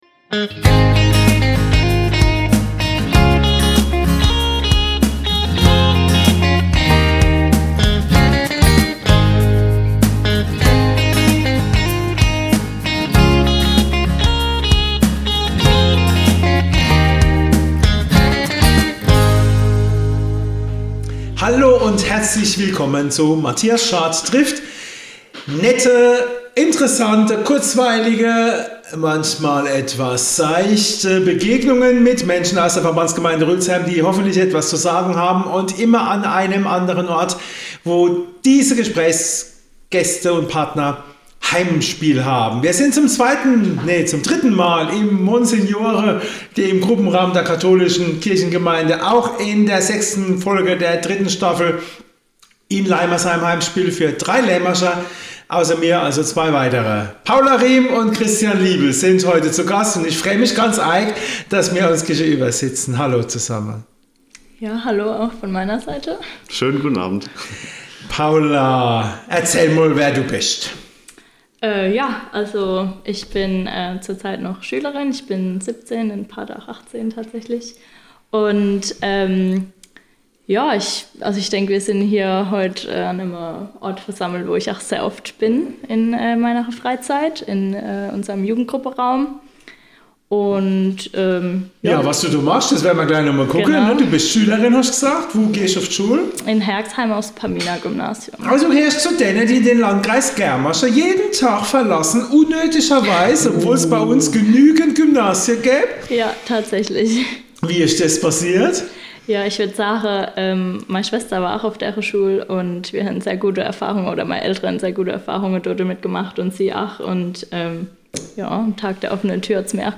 Die drei sprechen im wahrsten Sinne des Wortes über Gott und die Welt, denn es geht neben dem Engagement für die Kirche und als Messdiener auch um persönliche Erfahrungen durch Auslandsaufenthalte, Social Media und Künstliche Intelligenz.